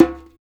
PERC.2.NEPT.wav